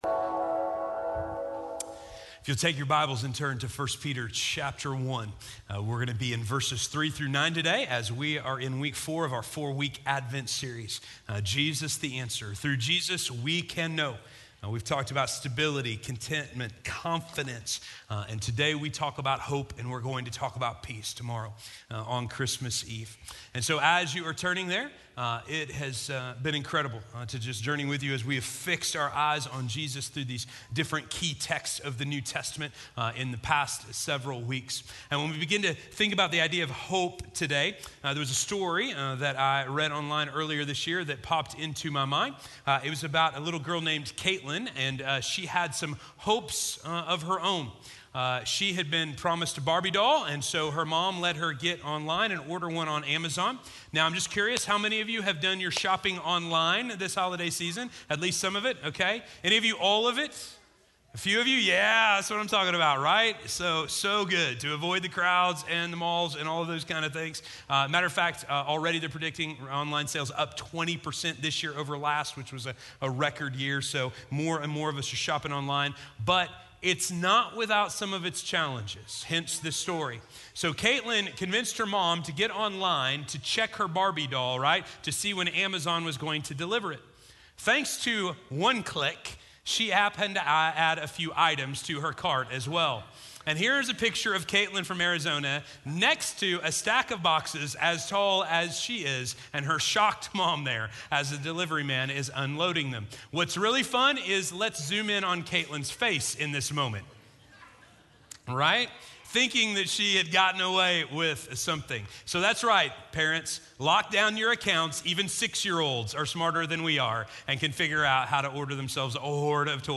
We Can Know...Hope - Sermon - Station Hill